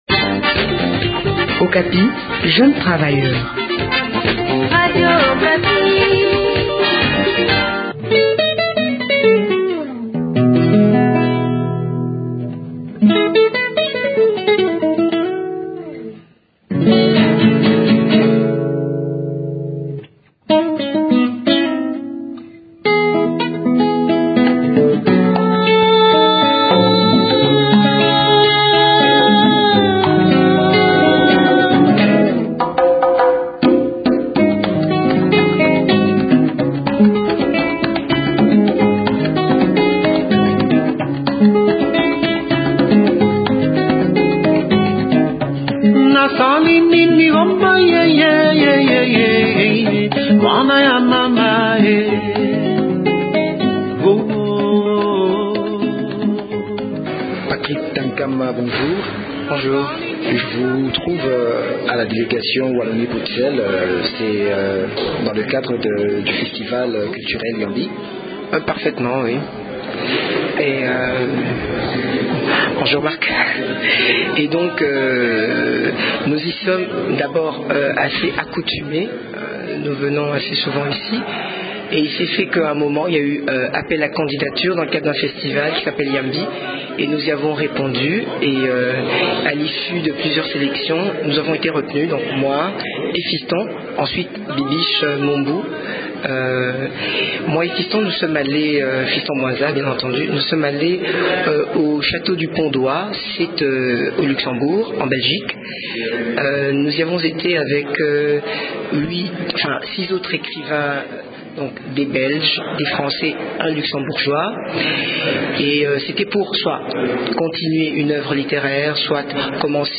entretien
dans les locaux de la délégation Wallonie-Bruxelles, à Kinshasa.